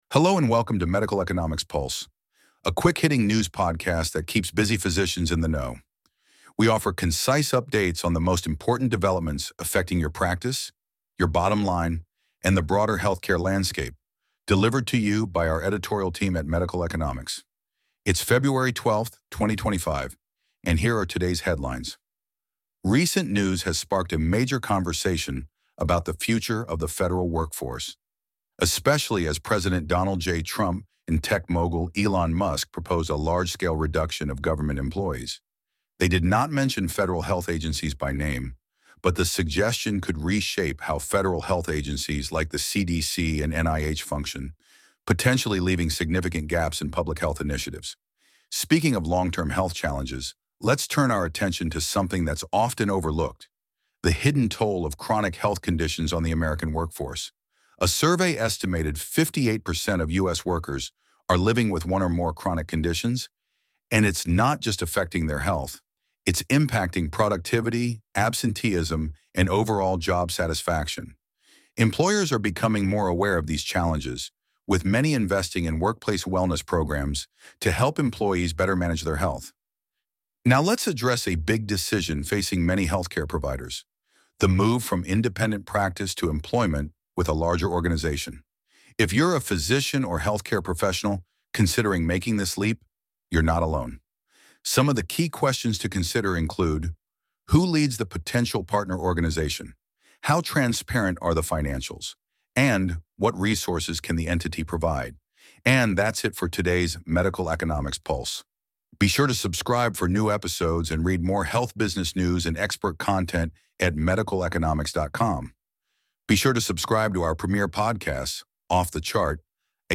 Hello and welcome to Medical Economics Pulse, a quick-hitting news podcast that keeps busy physicians in the know.